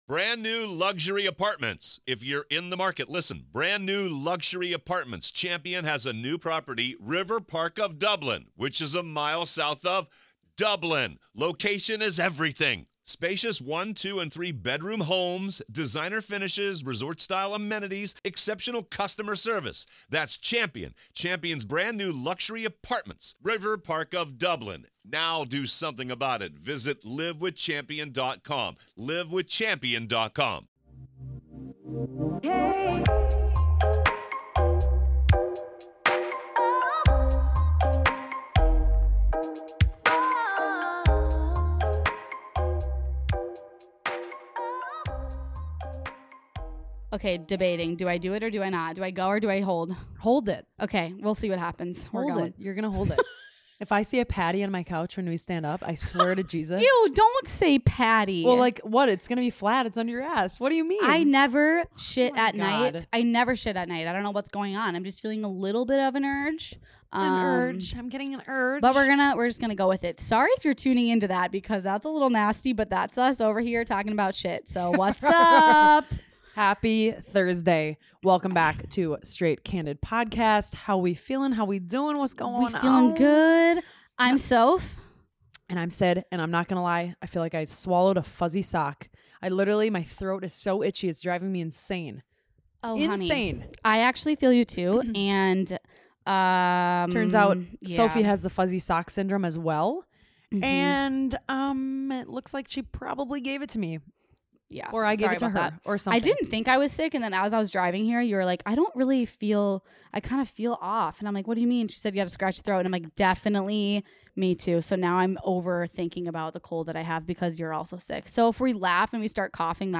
Don't mind the sniffles as both the girls are coming down with a cold.